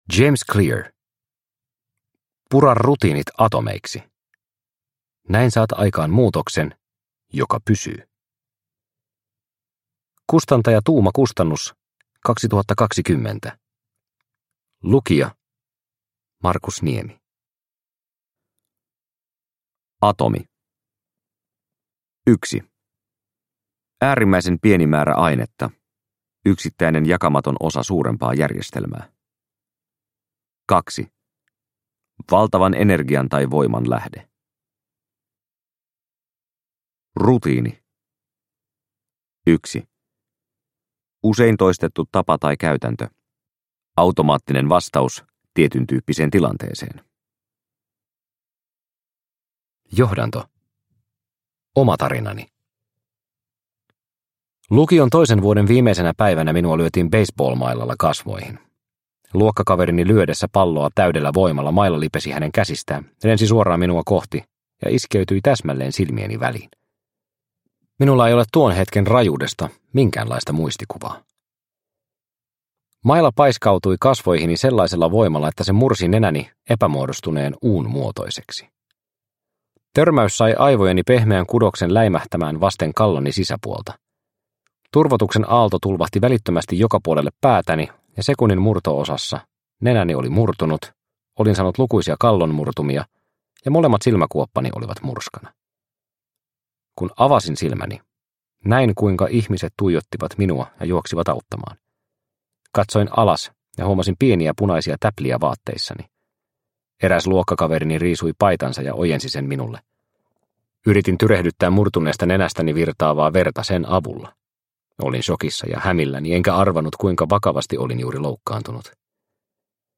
Pura rutiinit atomeiksi – Ljudbok – Laddas ner